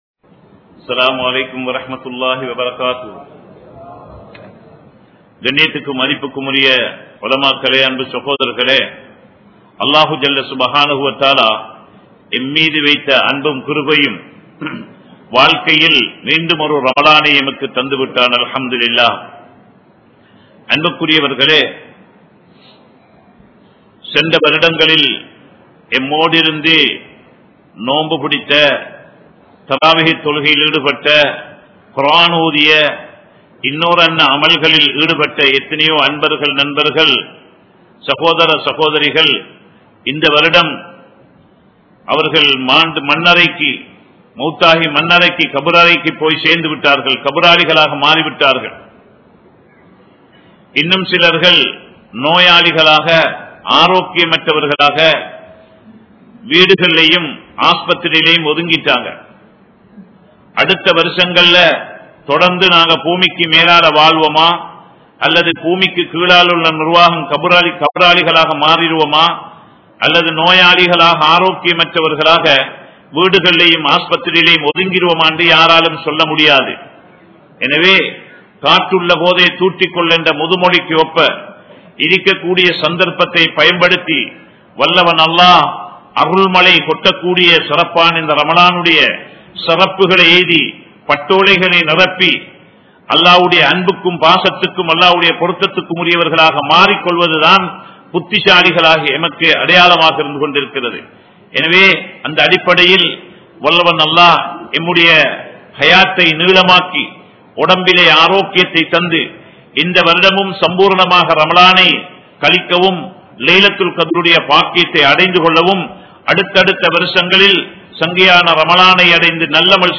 Sakrathil Thadu Maarum Manithan (சக்ராத்தில் தடுமாறும் மனிதன்) | Audio Bayans | All Ceylon Muslim Youth Community | Addalaichenai
Colombo 11, Samman Kottu Jumua Masjith (Red Masjith)